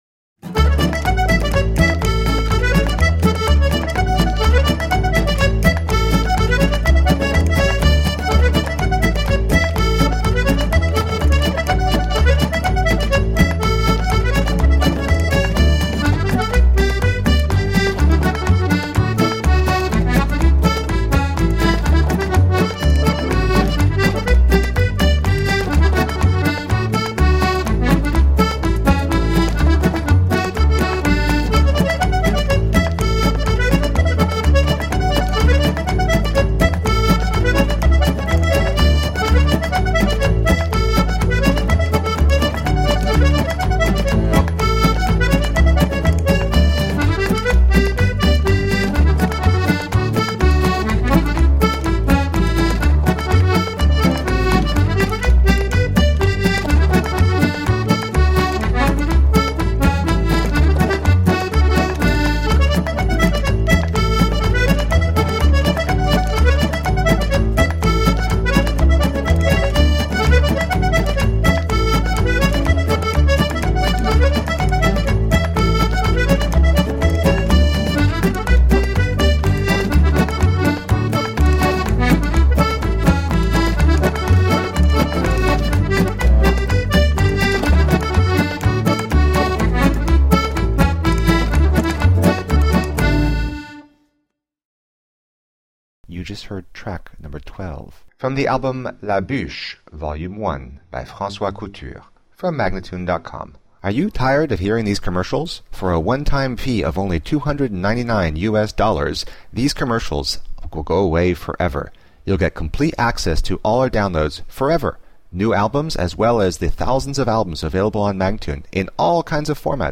Accordéon / Accordion
Guitar - Banjo - Spoons - Foot tapping
Bodhran - Washboard - Bones